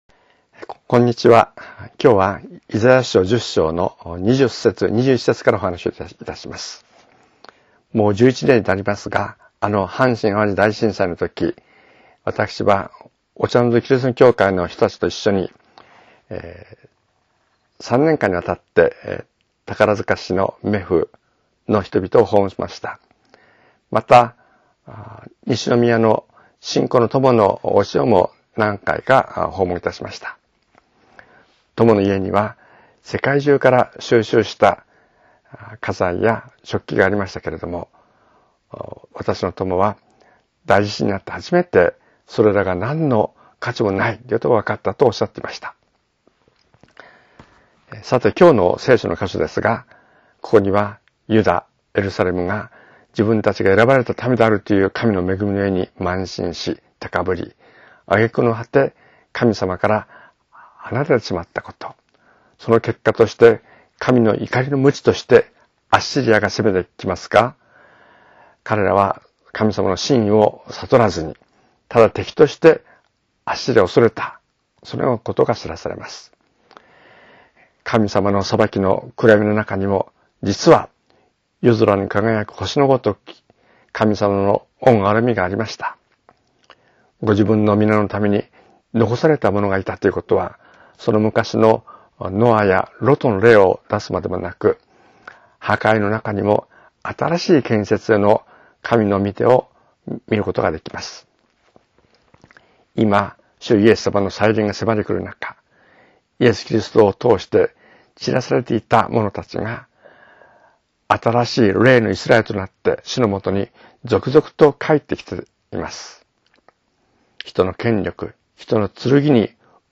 声のメッセージ